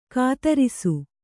♪ kātarisu